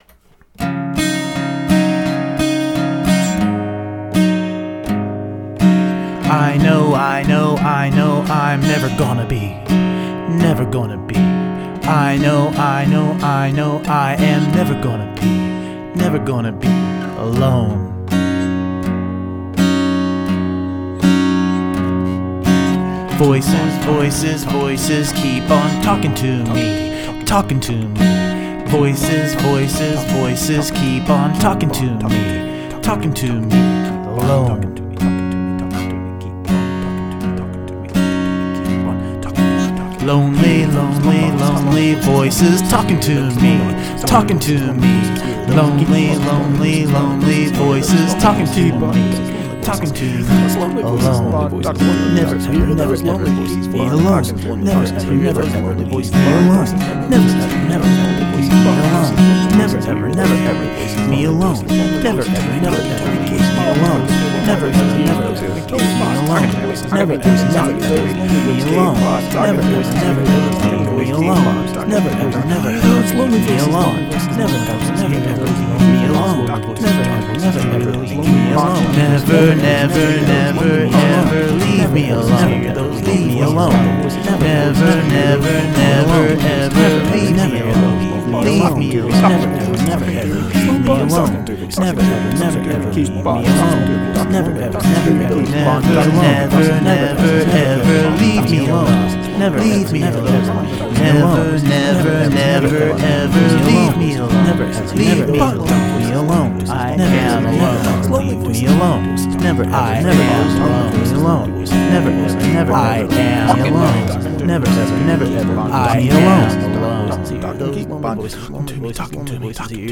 Although to be fair, most of these are shoddy recordings of barely more than sketches.
Real experimental, I like it.
I tell you what - I'm glad that the guitar was playing bright major chords.
Dadd4add9, A7sus4, G